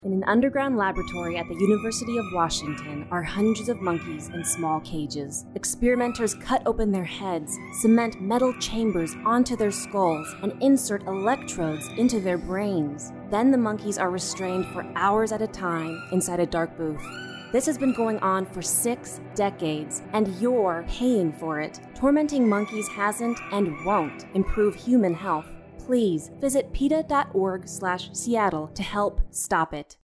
Instructions for Downloading This Radio PSA Audio File
MP3_uw_radio_ad.mp3